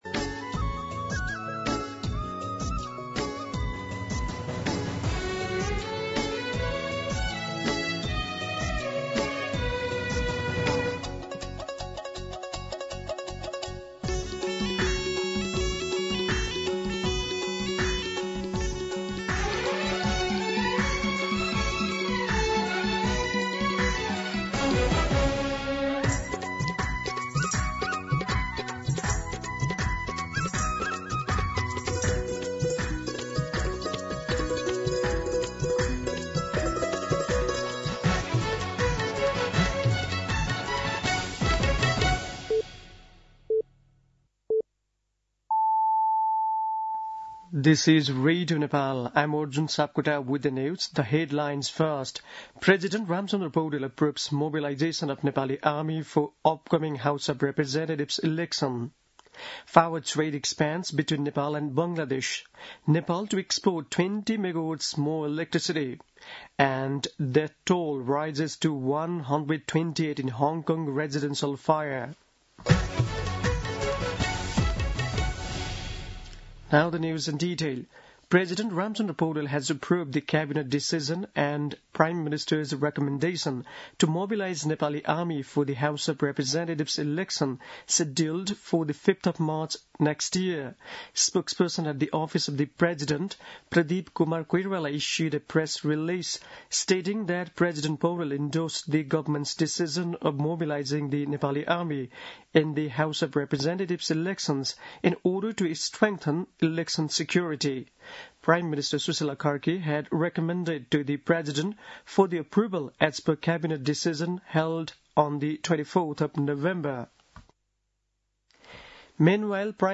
दिउँसो २ बजेको अङ्ग्रेजी समाचार : १२ मंसिर , २०८२
2pm-English-News-8-12.mp3